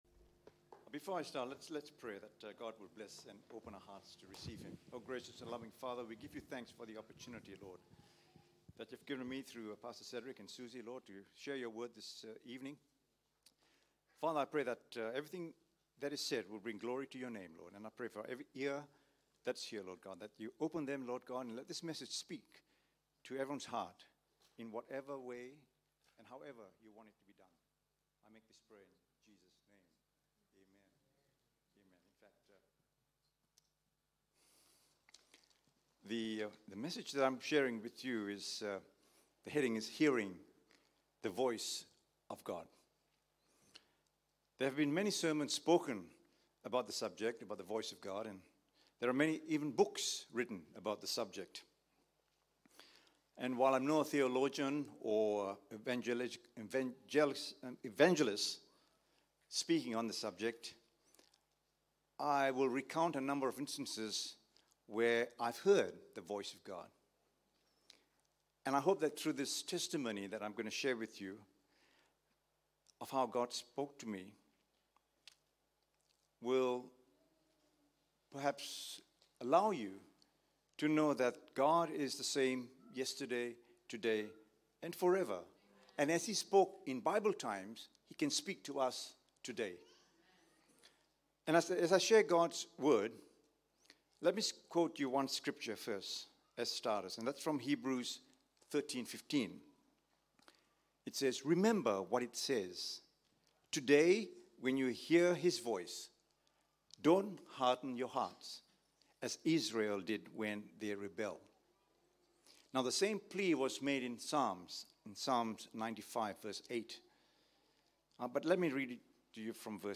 The full 34 minutes audio version of the sermon I presented at my church is available here, or at the link at the end of this essay.